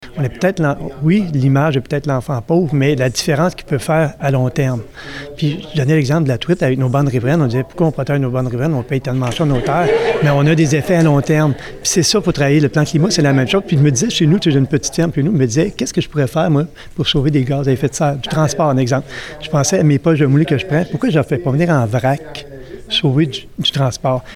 Lors d’une conférence de presse mardi à Gentilly, les instigateurs de ce projet ont donné les grandes lignes de celui-ci.